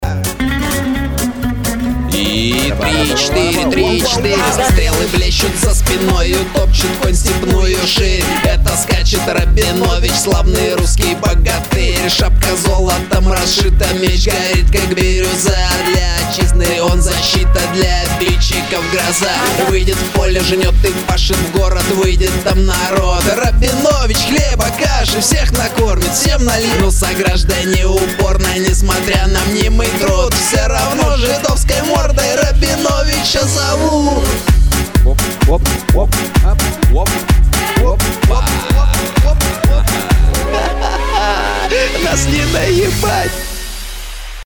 • Качество: 256, Stereo
Cover